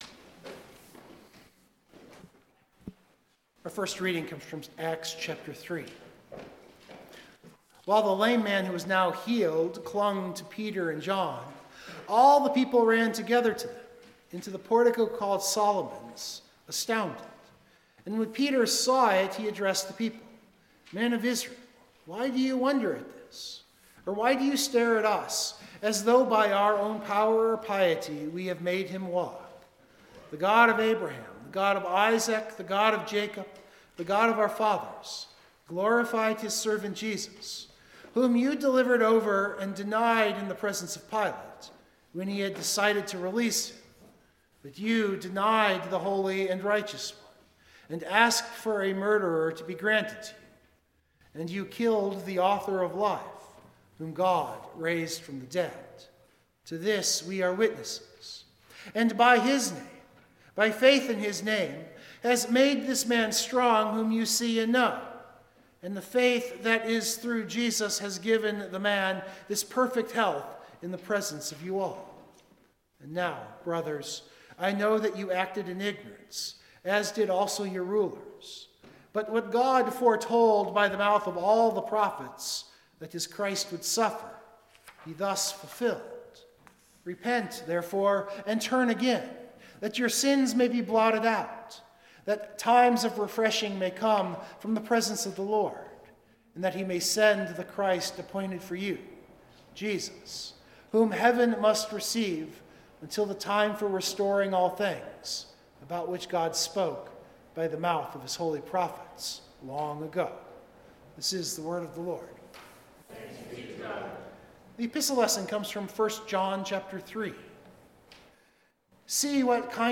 Biblical Text: Luke 24:36-49 Full Sermon Draft